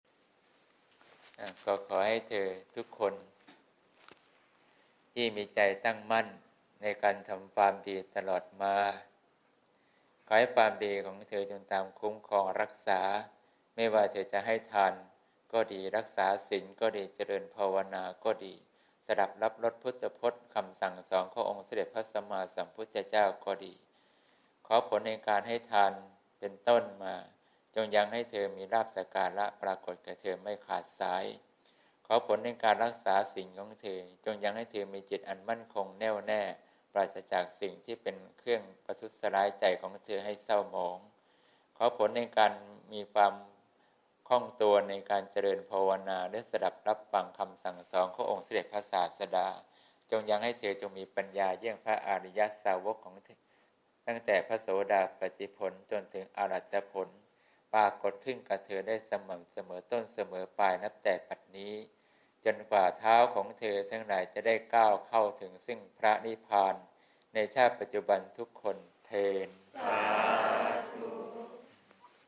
หลวงพ่อให้พร : วันที่ ๑๒ มกราคม ๒๕๖๘